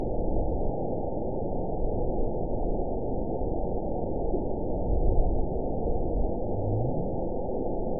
event 920293 date 03/13/24 time 01:27:37 GMT (1 year, 1 month ago) score 9.42 location TSS-AB01 detected by nrw target species NRW annotations +NRW Spectrogram: Frequency (kHz) vs. Time (s) audio not available .wav